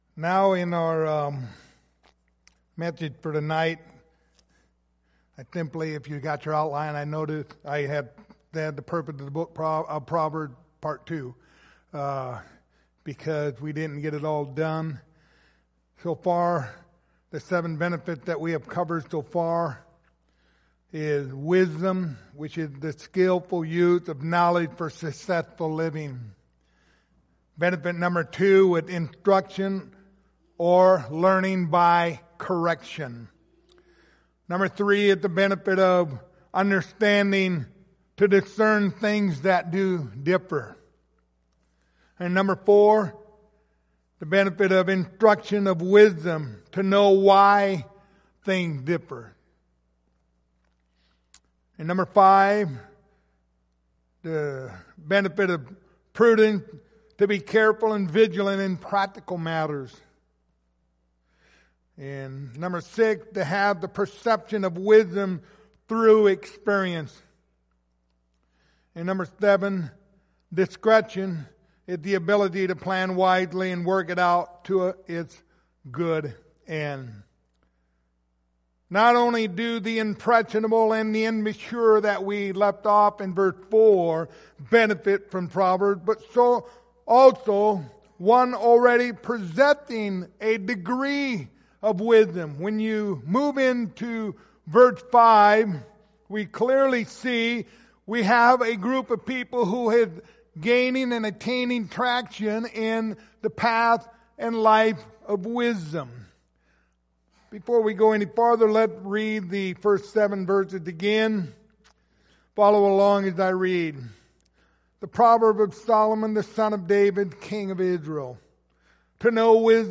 Passage: Proverbs 1:1-7 Service Type: Sunday Evening